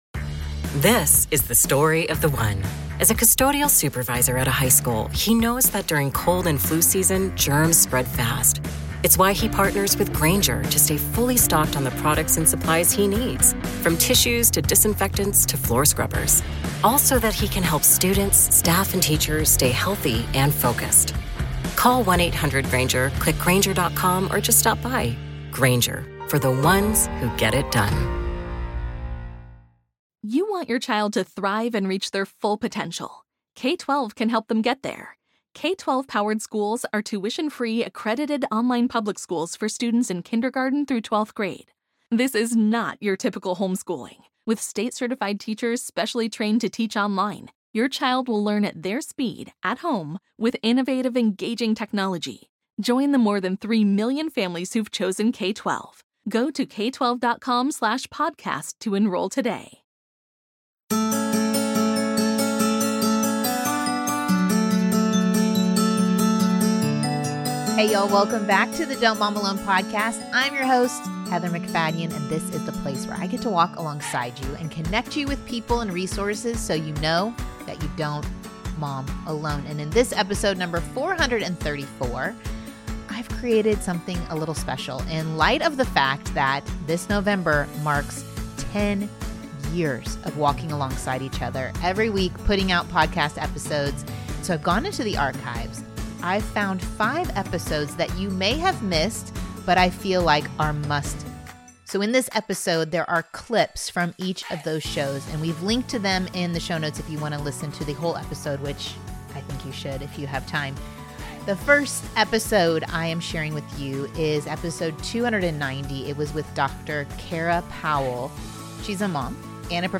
As we inch closer to 500 episodes, I selected clips from 5 episodes over the years for us to listen to together this week.